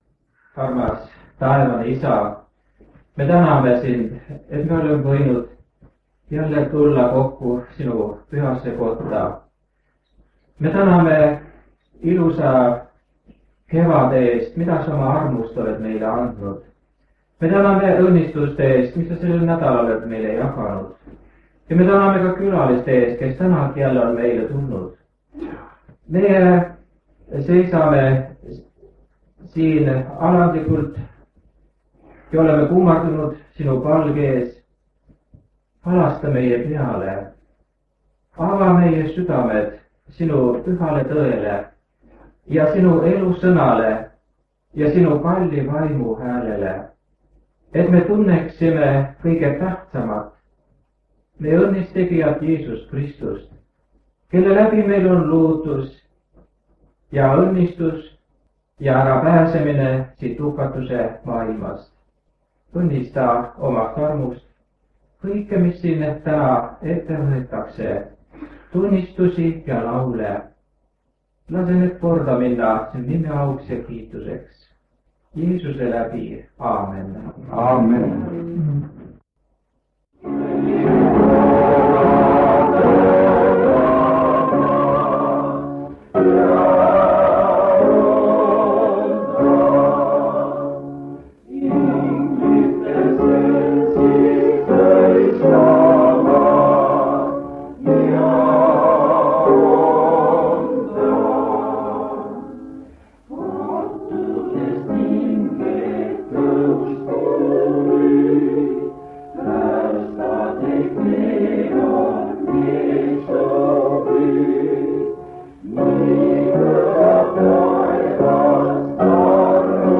Paide adventkirikus on Evangeeliuminädal ja aasta on 1977.
Kuigi lintmaki lindi karbil info puudus, tuvastasime kõneleja hääle järgi.
Keegi naine loeb ühte huvitavat raamatut.
Jutlused